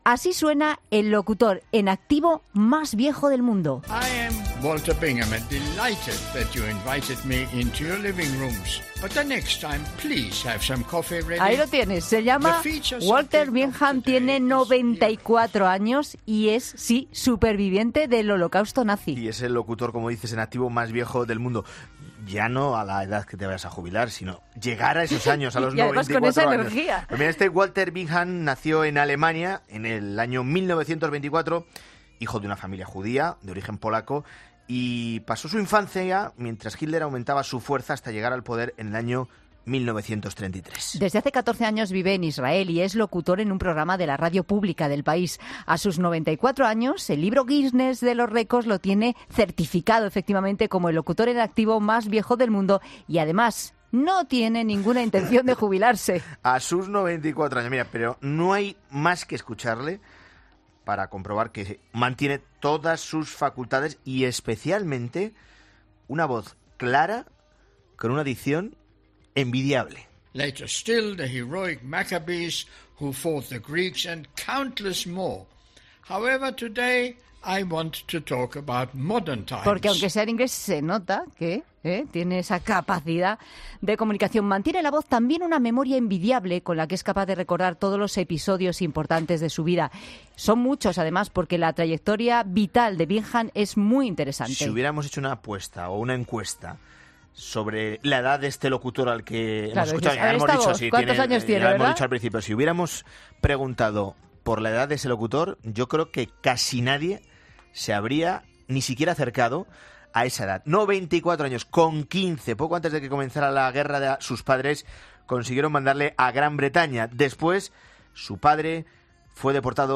No hay más que escucharle para comprobar que mantiene todas su facultades y especialmente una voz clara y con una dicción envidiable.